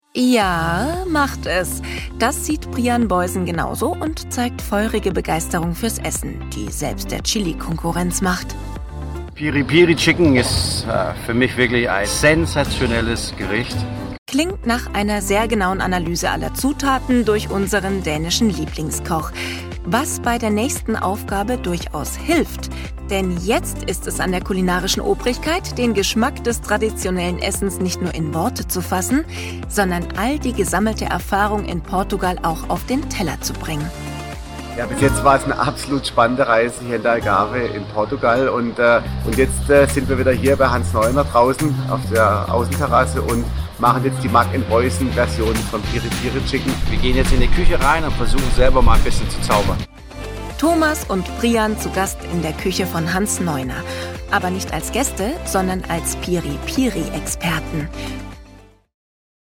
Europa-Park | Kochsendung | Off-Stimme